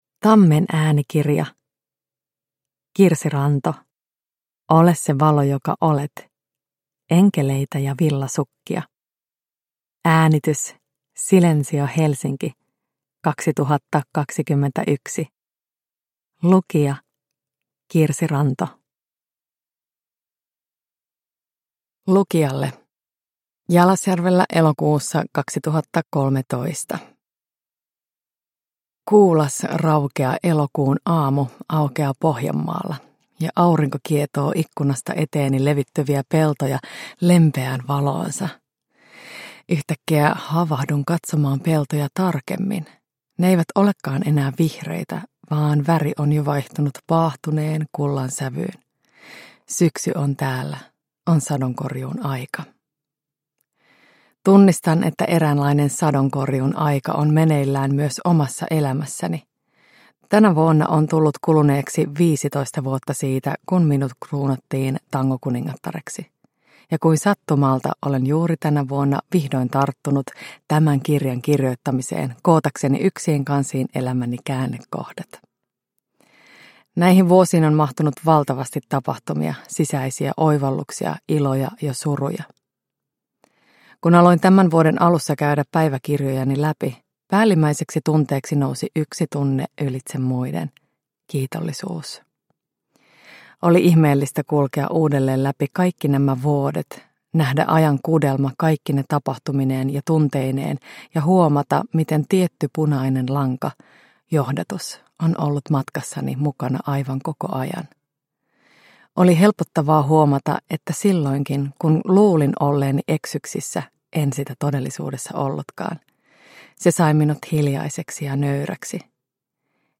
Ole se valo joka olet – Ljudbok – Laddas ner